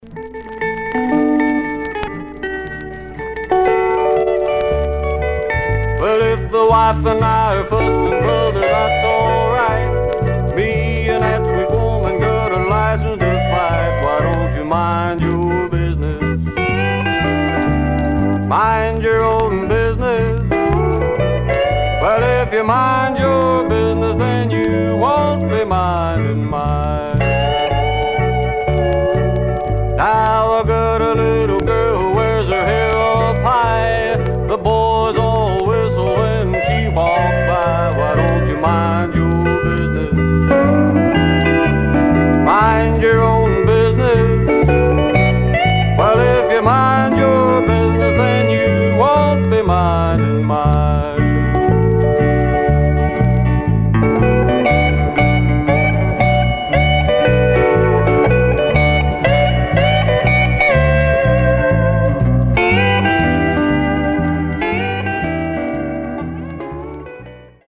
Some surface noise/wear Stereo/mono Mono
Country